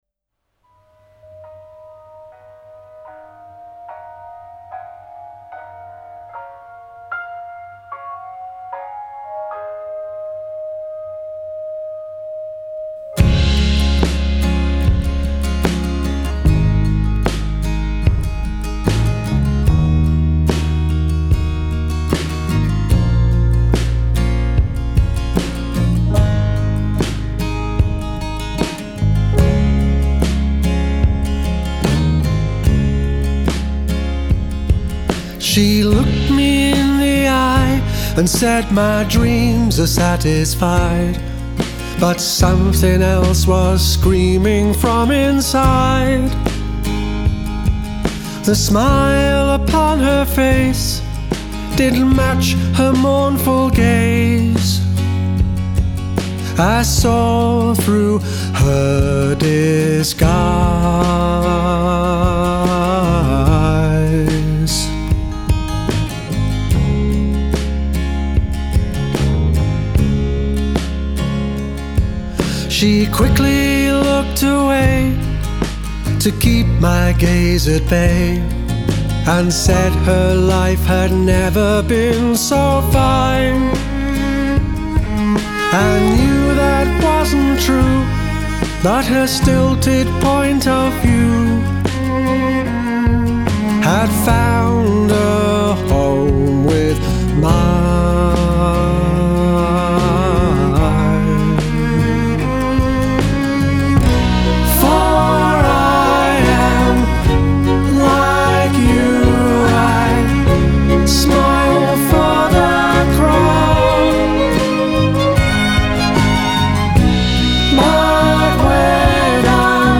who also played the very tasteful piano part
oh, those sweet, sweet strings!